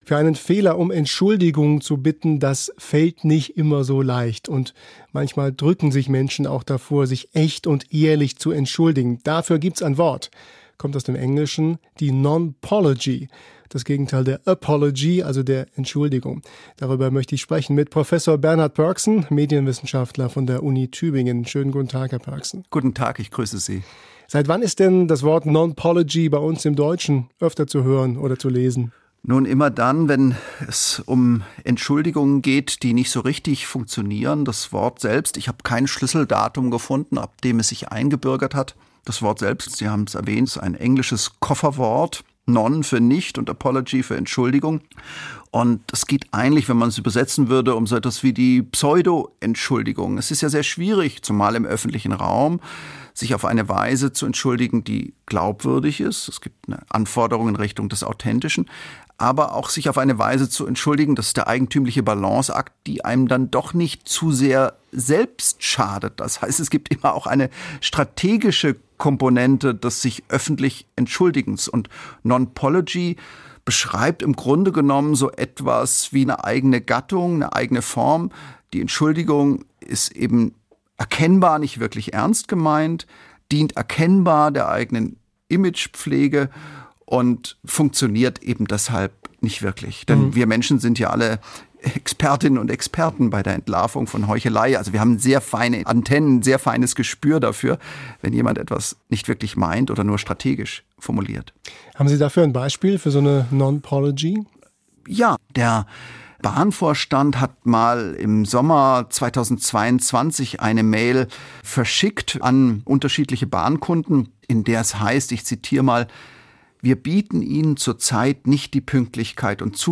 Nonpology - erklärt von Bernhard Pörksen
Im Englischen heißt das Wort für Entschuldigung „apology“. Es gibt aber auch den Anglizismus Nonpology - und was es damit auf sich hat, erklärt der Medienwissenschaftler Prof. Bernhard Pörksen von der Universität Tübingen.